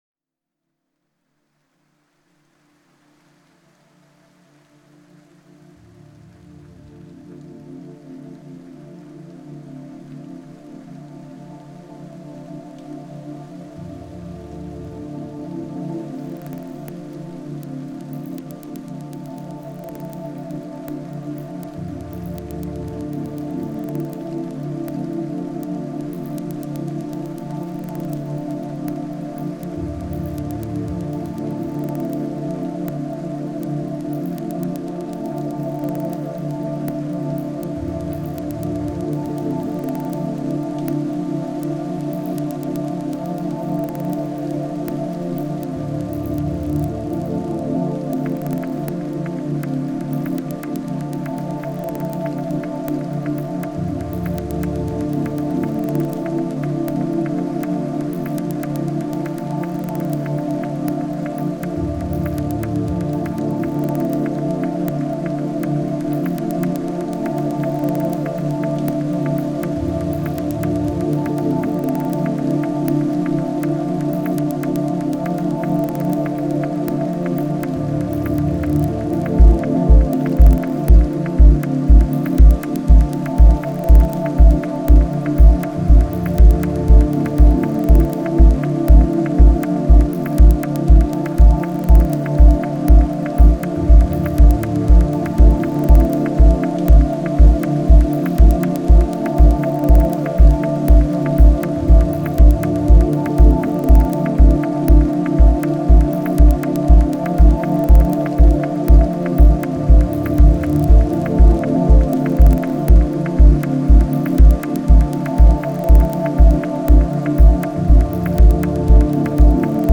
Genre: Ambient/Deep Techno/Dub Techno.